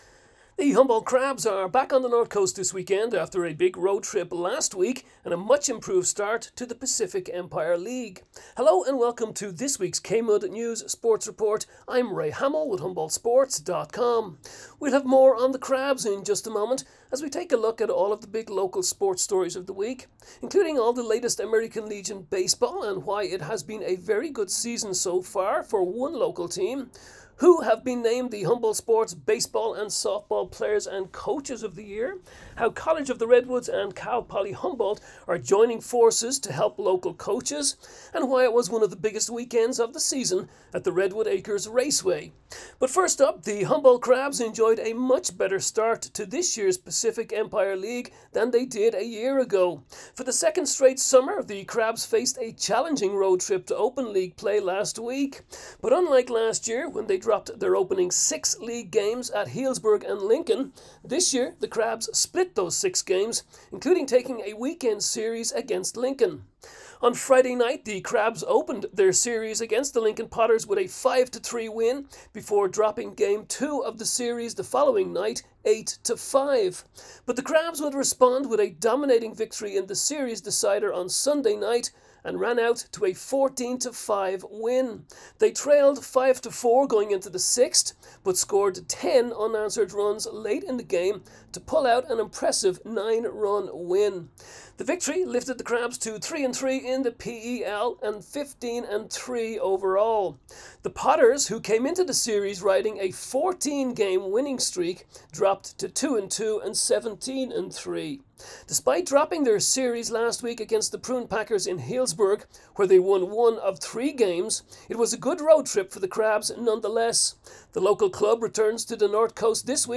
KMUD News Sports Report June 27